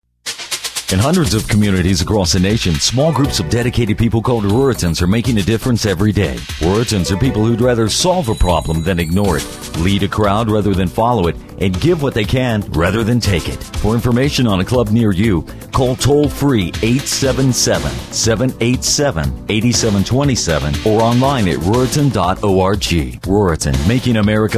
Ruritan Radio Spot - 30 seconds - Spot 2